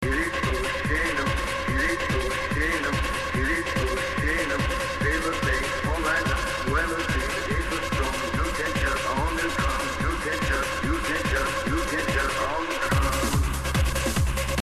I have no idea what the person is saying really